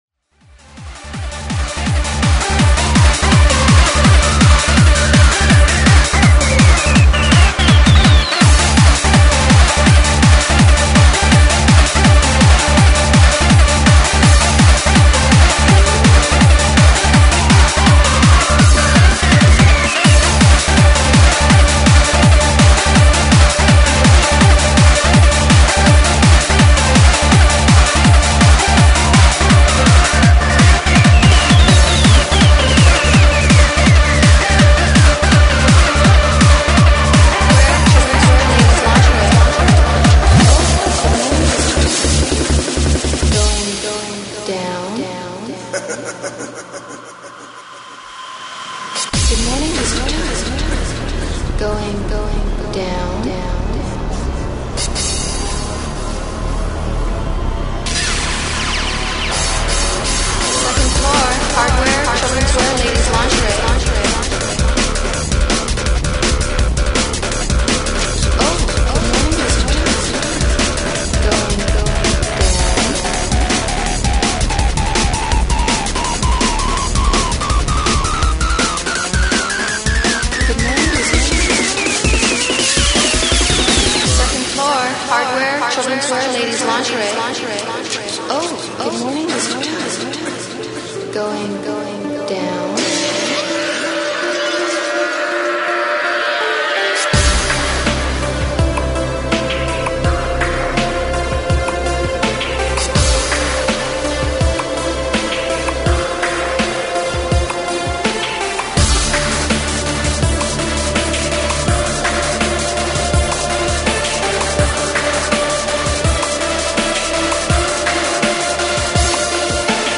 Freeform/Finrg/Hardcore